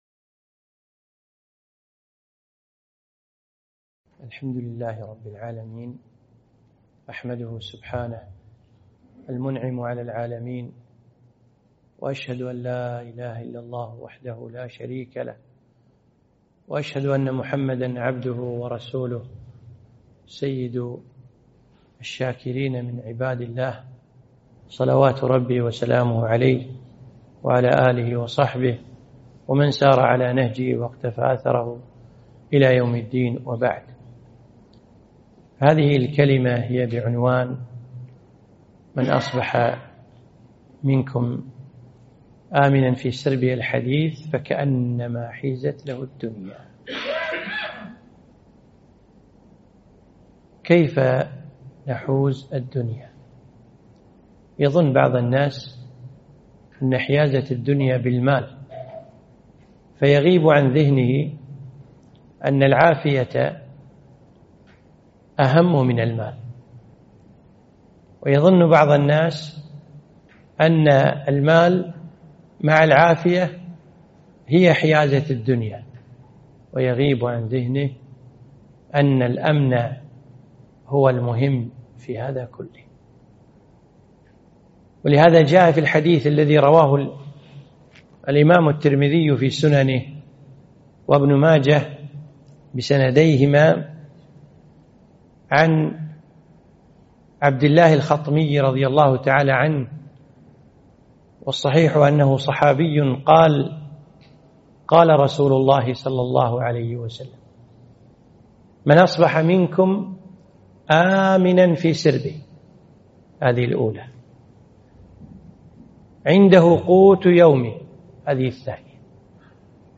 محاضرة - فكأنما حيزت له الدنيا